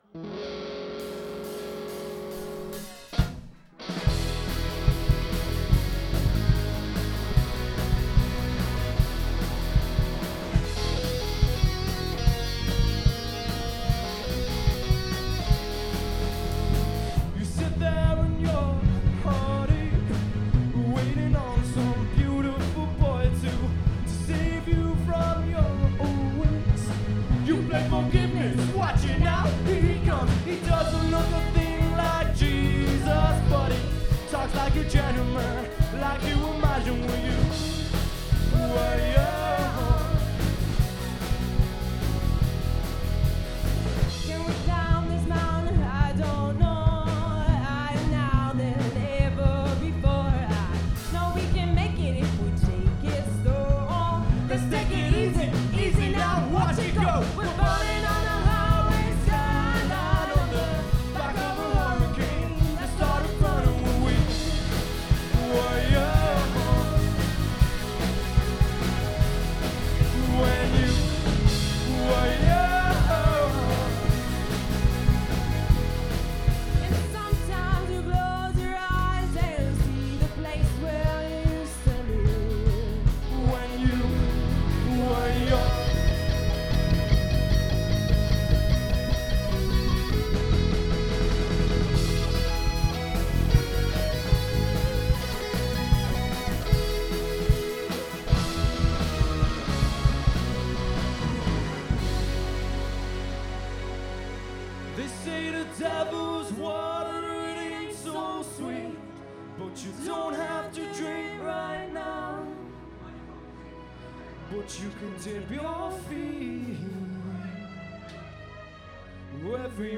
About a month ago, however, I just decided to dust off the interface and record one of our gigs live because some people were going to record some video at it, so I just ran all the channels through the interface (a tascam us1800) and clicked record.
One thing I really am unhappy with in it is the sound of the guitar, but that was my fault for not paying enough attention at the gig. I think the problem might have been mic placement or something, but it sounds harsh in the recording, and at the gig a nice portion of the guitar sound was coming from the actual amp, the gig was only in a bar after all, we only put a bit of guitar through the PA to make sure it carried to the far end of the bar.
If it's not okay to post this here then maybe someone can delete it, but I figure it's a "newbie question" Finally, I couldn't upload this here without saying that this song was at the end of a fairly long setlist and the female vocalist and the bass player must have been feeling it, they are far better than that usually.